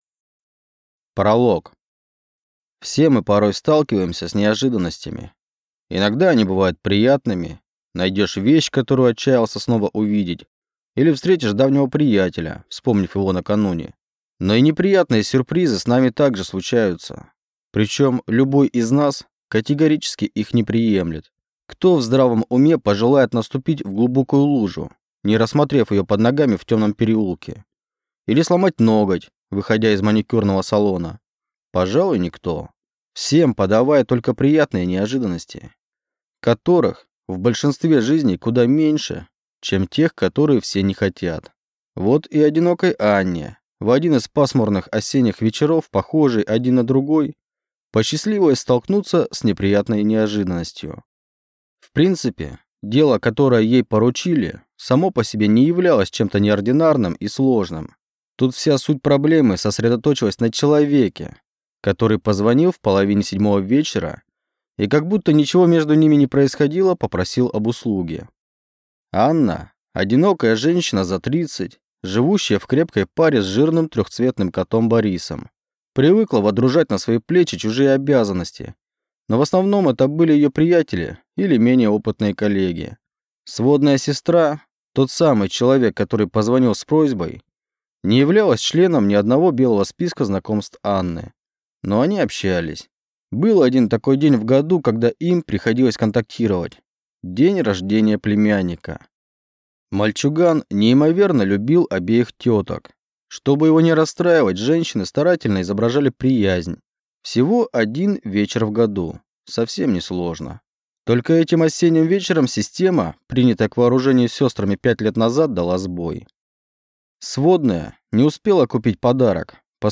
Аудиокнига В плену сознания | Библиотека аудиокниг